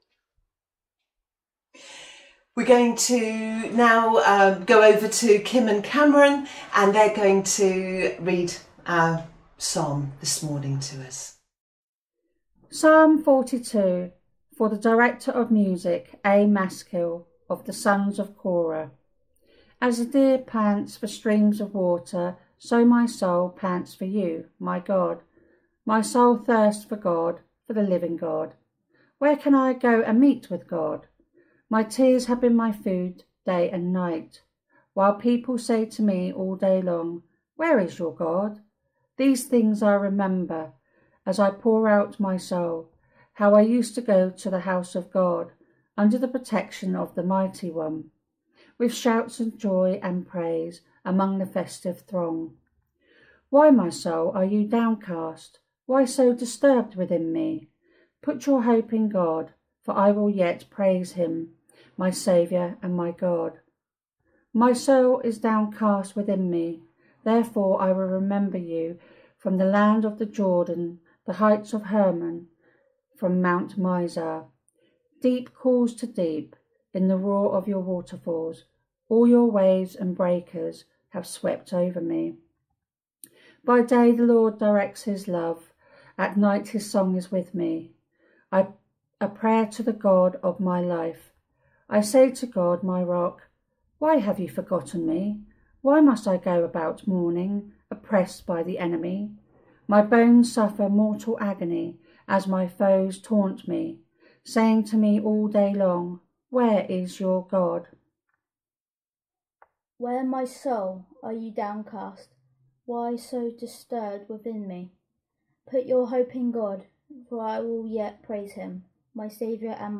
July 12th sermon.mp3